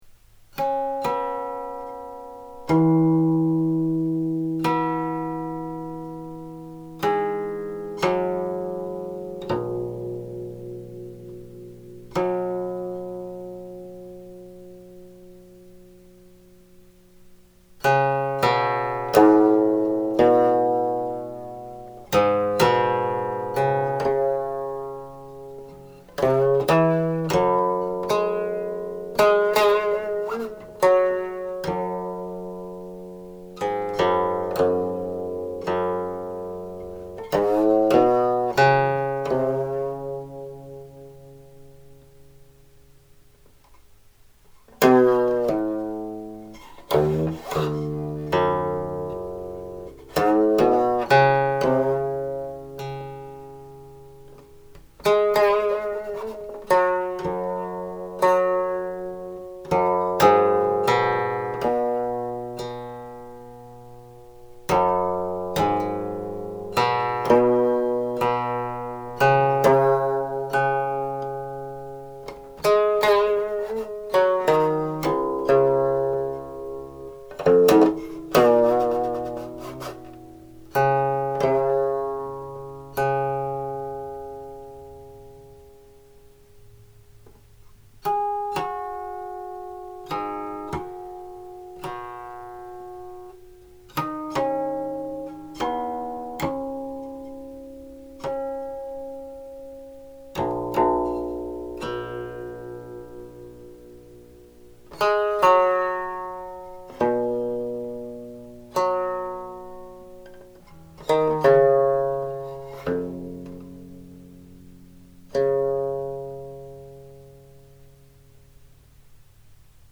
This 1539 setting is completely syllabic; there are no slides and few indications of other ornaments. It is said to be a shang mode melody presumably on the basis that the main tonal center is a note equivalent to the open second string (called the shang string). However, unlike most early Ming shang mode melodies its other main tonal center is yu (la) rather than gong (do).
00.00 (Instrumental prelude adapted from the closing harmonics)